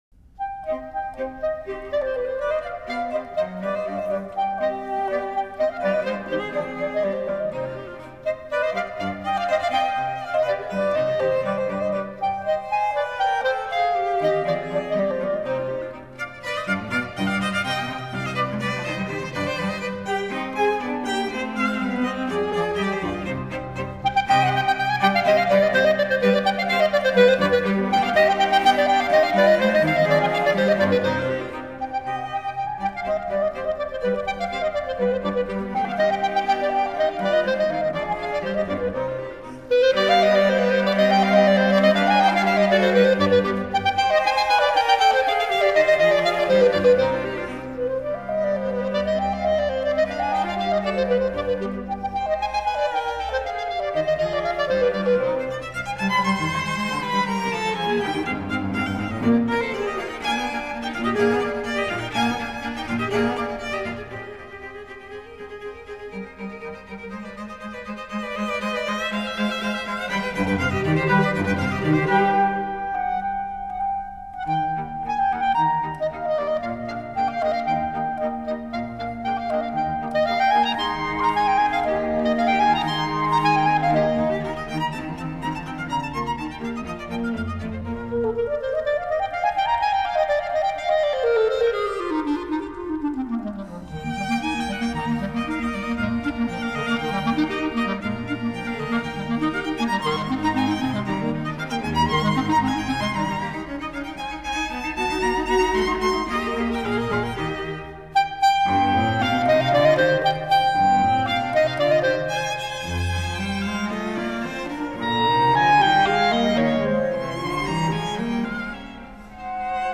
音乐类型: Classical
音色纯净，清澈优美的单簧管吹奏，非常好听！